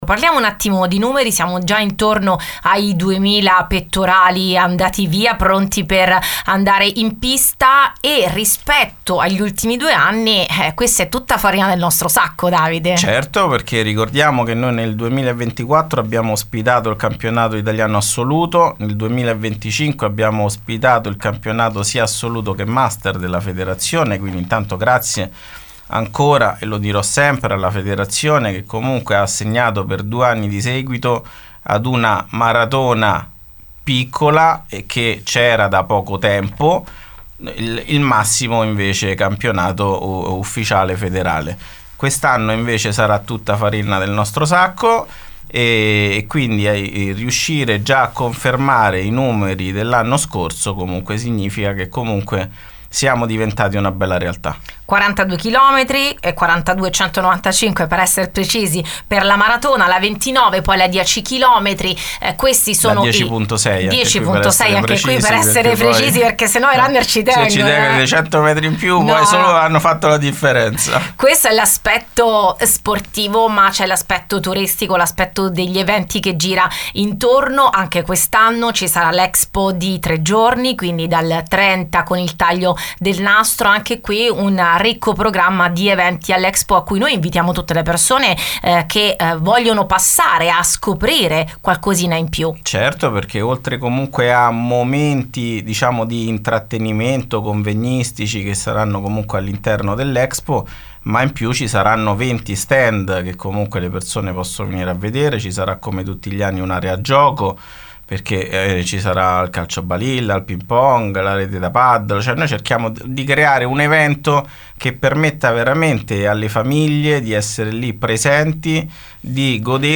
Il gruppo Mondo Radio  seguirà ufficialmente tutti gli eventi ed ha ospitato in diretta